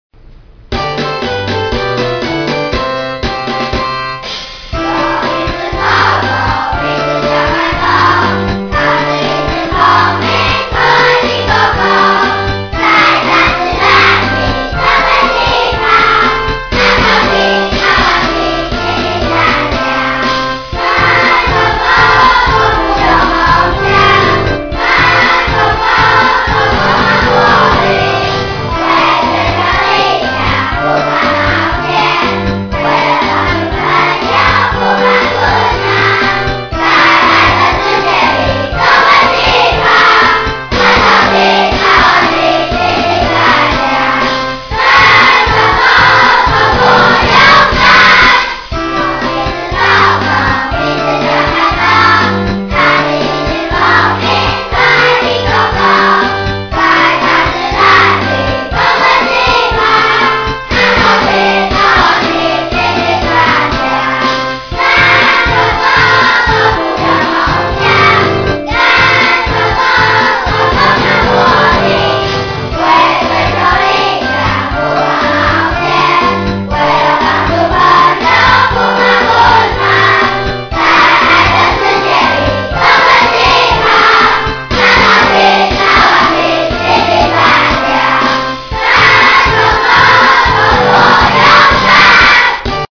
嘉義縣頂六國小九十一學年度四年級班級經營之[主動學習
這是一首歌 我們把它叫做